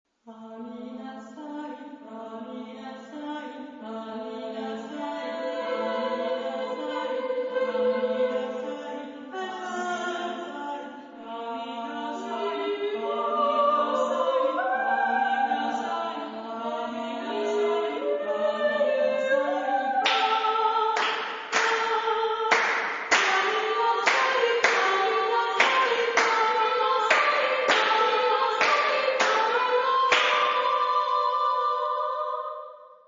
Genre-Stil-Form: weltlich ; Gedicht ; zeitgenössisch
Tonart(en): polytonal